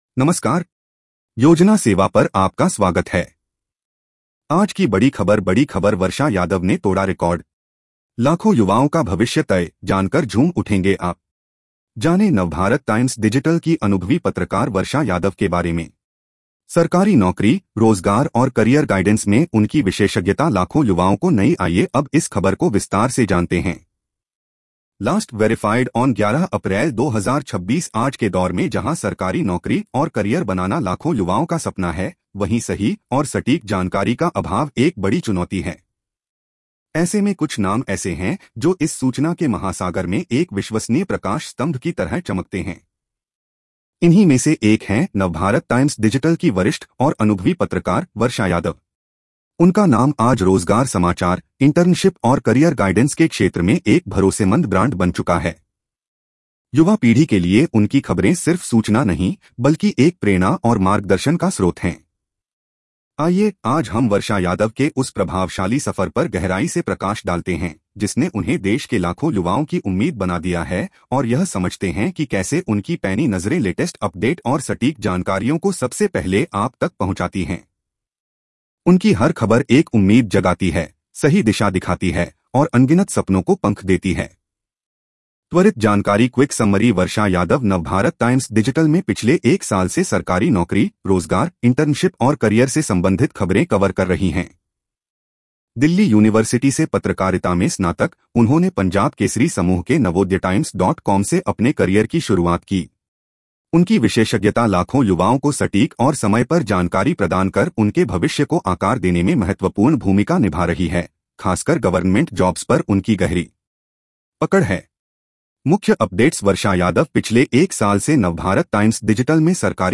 News Audio Summary
इस खबर को सुनें (AI Audio):